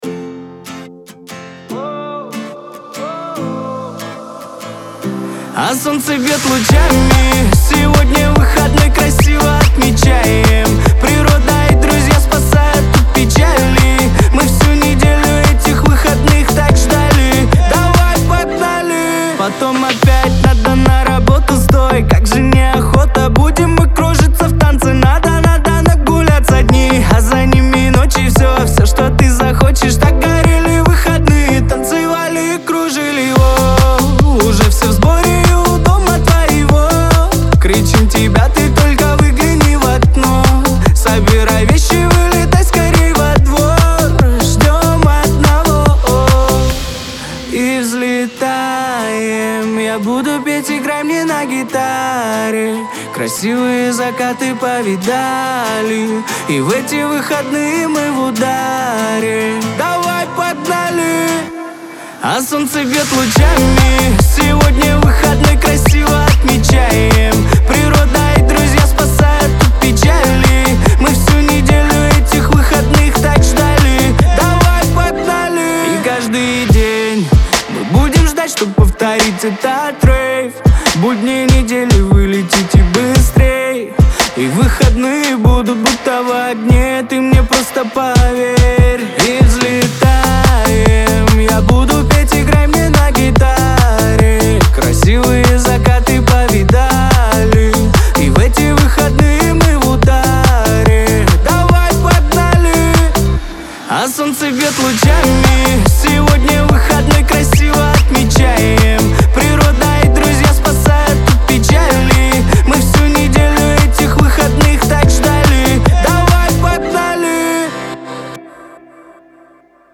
pop , диско
Веселая музыка , танцы , эстрада